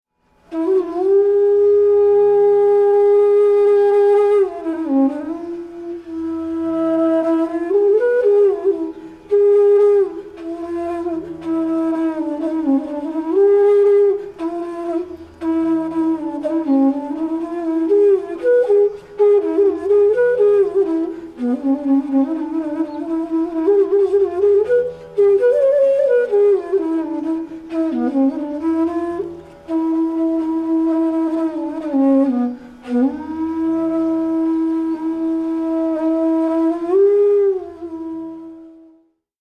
Aroha: SrGPDnS
Avroh: SnDPGrS
Pakad: variable, e.g. SrGPG; GPDnrS
Jazz: Mixolydian b2 (no 4th)
• Tanpura: Sa–Pa